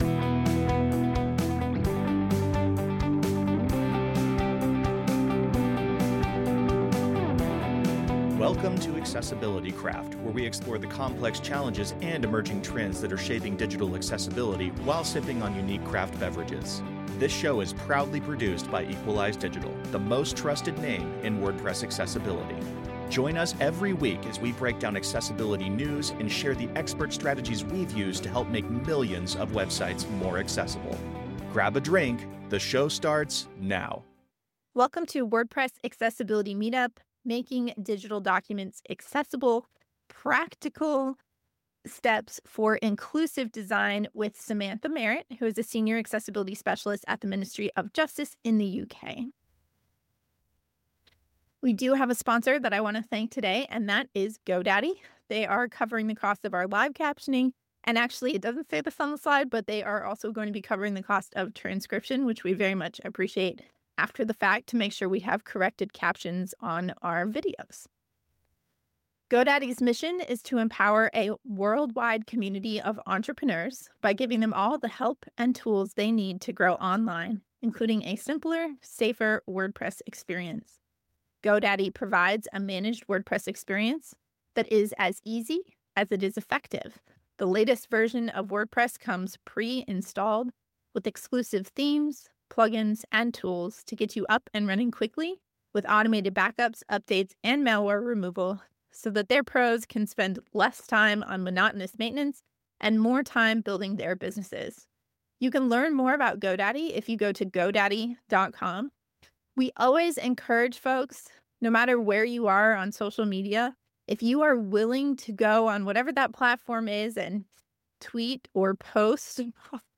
Instead of focusing only on rules or checklists, she shared practical tips that participants could start using right away, helping them better understand why document accessibility matters for both compliance and inclusion. WordPress Accessibility Meetups are a 100% free and virtual community resource that take place via Zoom webinars twice a month.